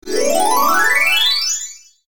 MG_sfx_travel_game_bonus.ogg